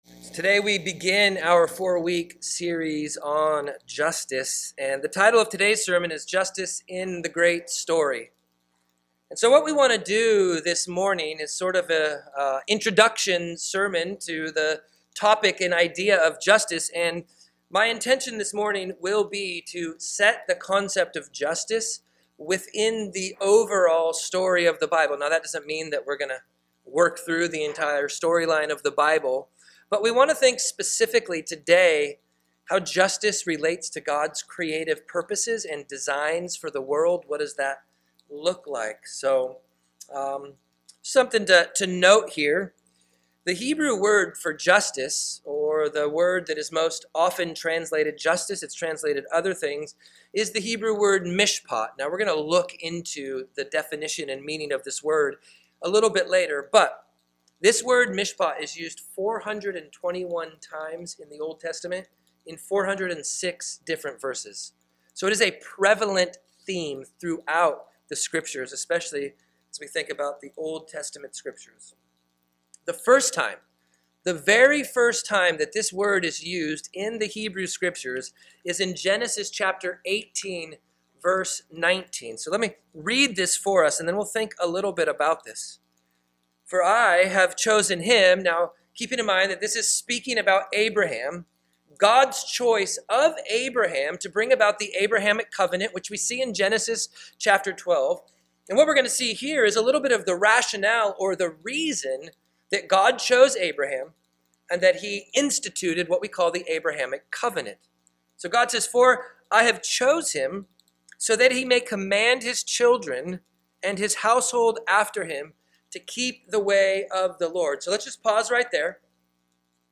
Feb 09, 2022 Justice in the Great Story MP3 SUBSCRIBE on iTunes(Podcast) Notes Discussion Sermons in this Series This is our first of four sermons looking into biblical justice. This sermon sets the understanding of biblical justice within God's creative design for the world and reveals that Jesus is the ultimate justice of God.